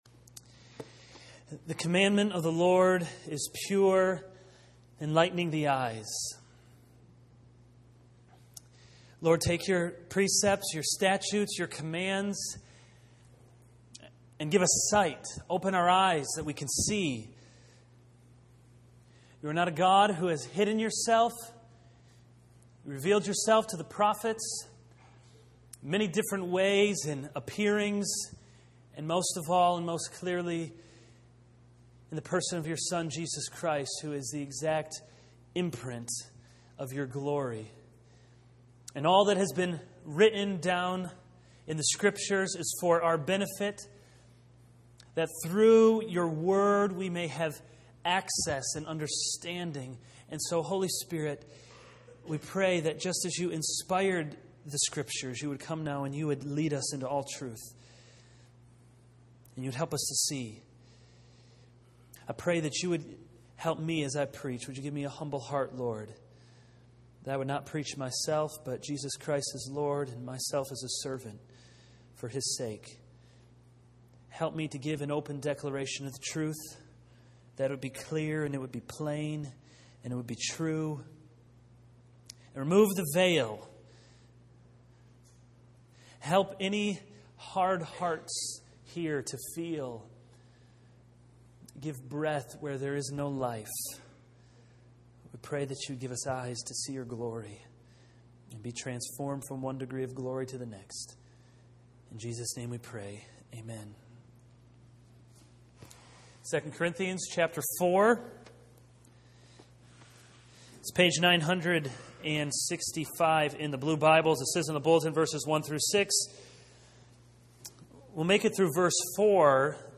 This is a sermon on 2 Corinthians 4:1-6.